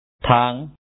tha2aN way, road